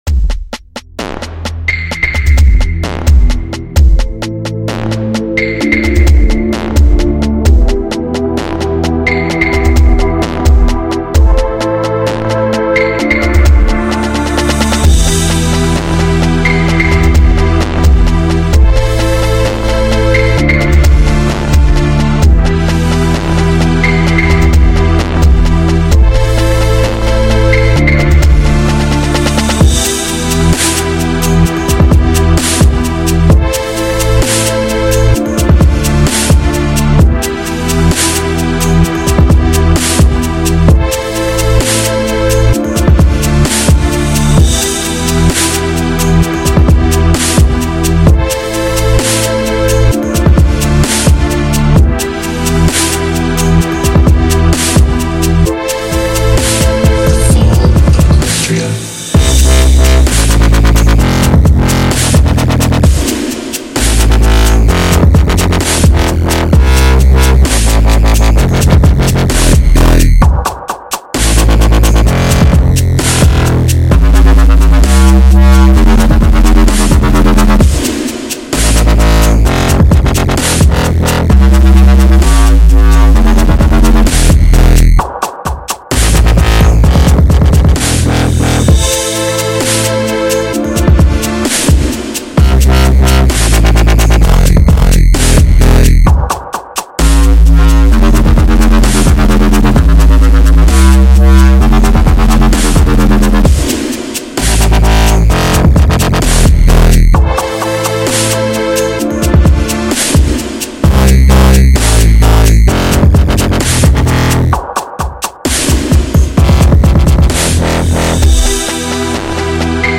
This time, Fallout Equestria themed Dubstep Mix.